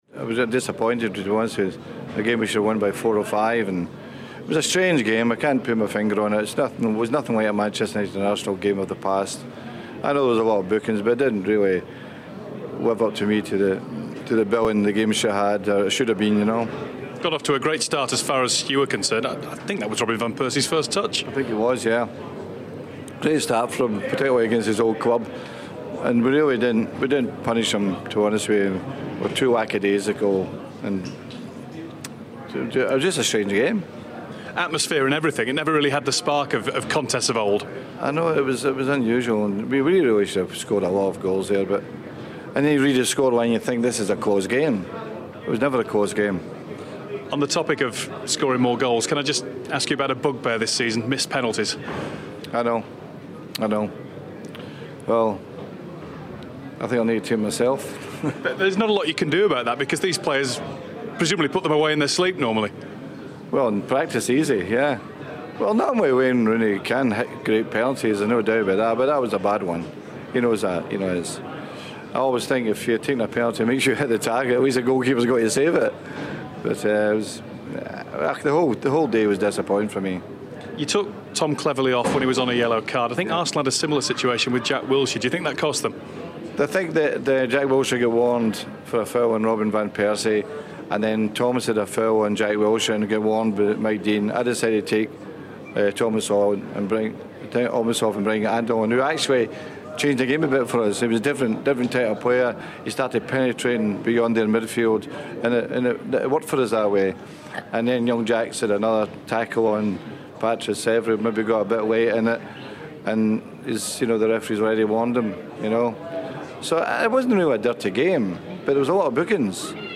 Manchester United manager Sir Alex Ferguson speaks to the BBC after United's 2-1 win over Arsenal